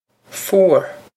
fuar foo-er
This is an approximate phonetic pronunciation of the phrase.